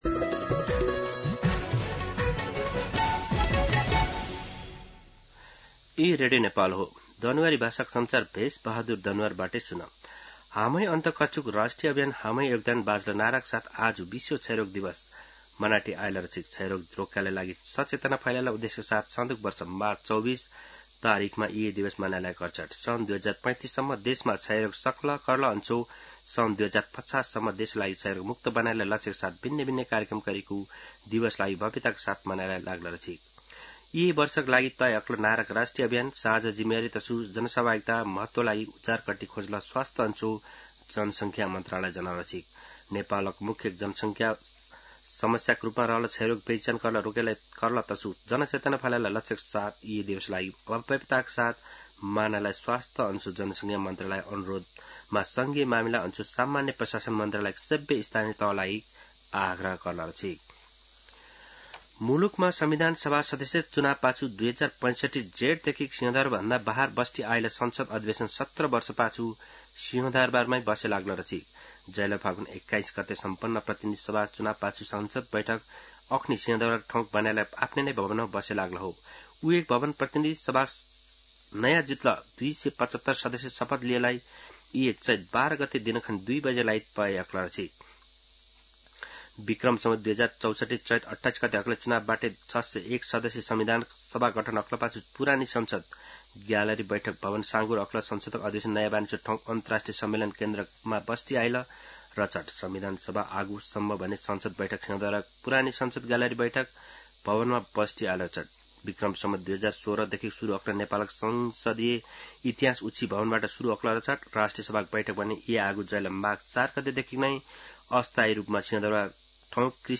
दनुवार भाषामा समाचार : १० चैत , २०८२
Danuwar-News-10.mp3